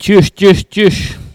Cri pour appeler les cochons ( prononcer le cri )
Langue Maraîchin